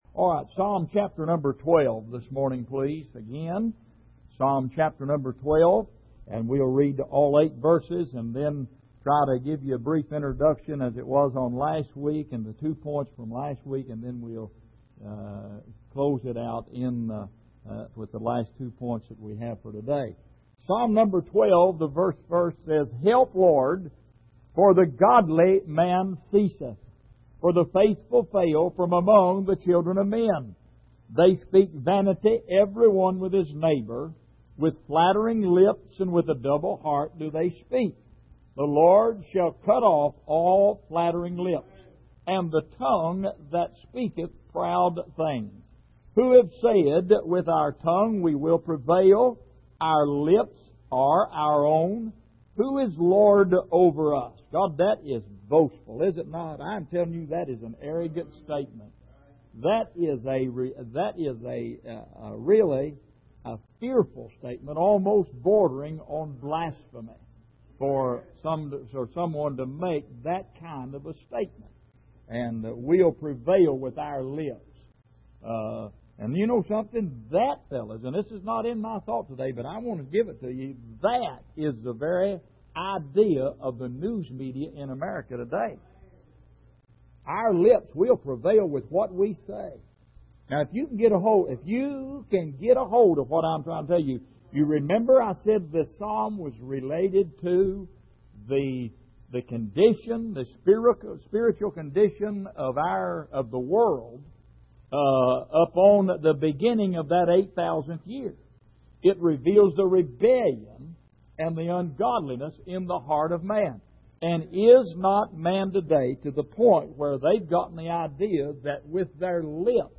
Exposition of the Psalms Passage: Psalm 12:1-8 Service: Sunday Morning Psalm 12